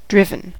driven: Wikimedia Commons US English Pronunciations
En-us-driven.WAV